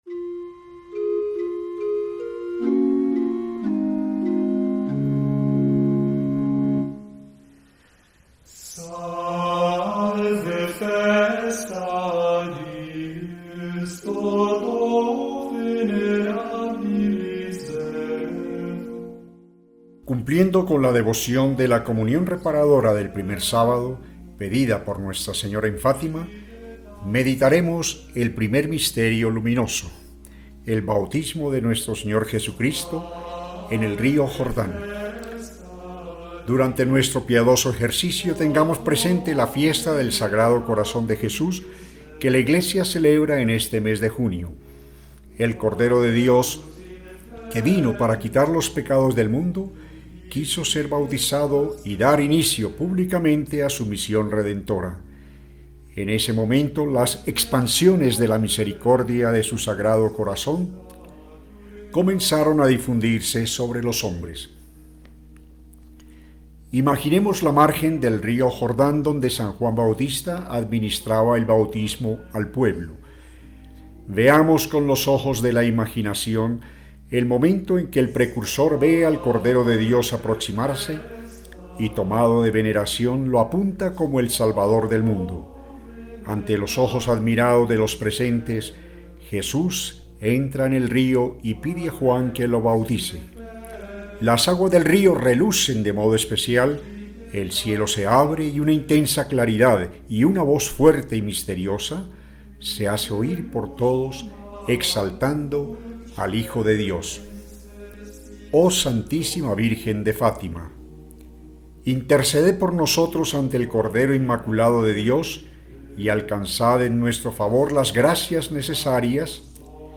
Santo rosario leído por los Caballeros de la Virgen